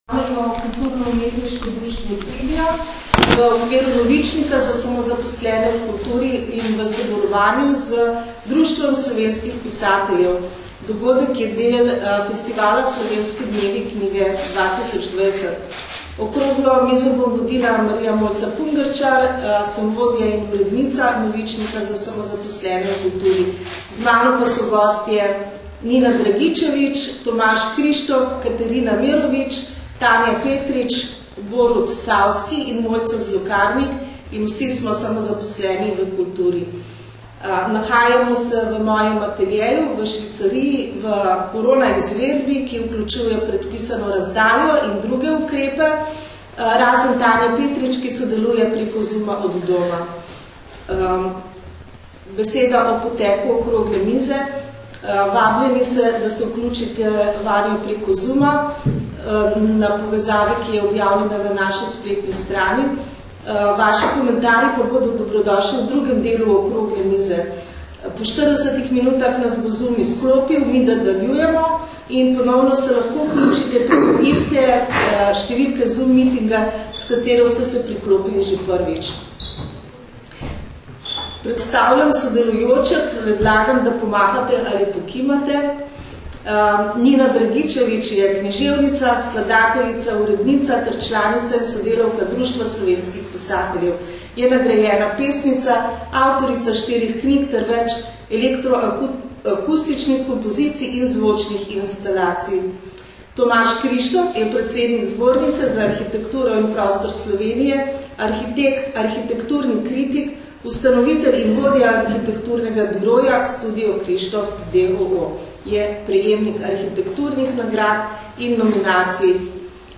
Okrogla miza POMEN NAGRAD ZA SAMOZAPOSLENE V KULTURI na Slovenskih dnevih knjige
KUD Trivia je v okviru Novičnika za samozaposlene v kulturi v sodelovanju z Društvom slovenskih pisateljev pripravilo okroglo mizo Pomen nagrad za samozaposlene v kulturi. Dogodek je bil del festivala Slovenski dnevi knjige.
Nagrade-KUD-Trivia-Zvocni-posnetek-okrogle-mize.mp3